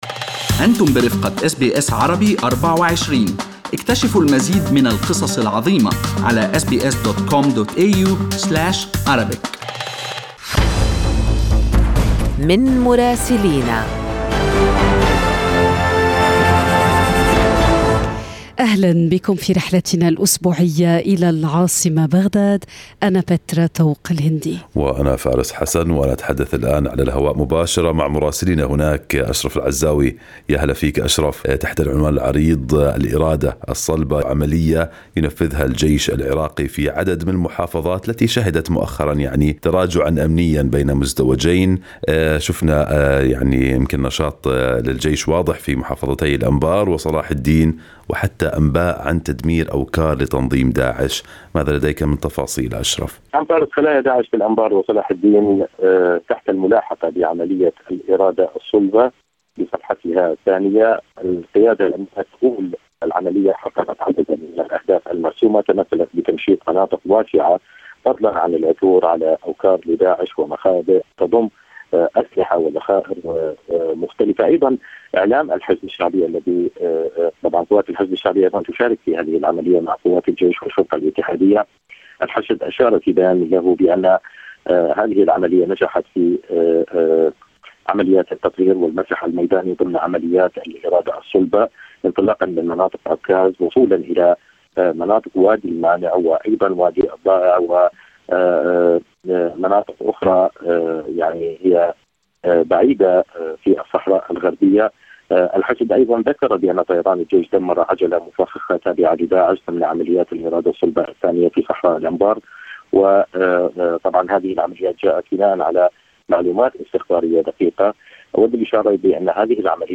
يمكنكم الاستماع إلى تقرير مراسلنا في بغداد بالضغط على التسجيل الصوتي أعلاه.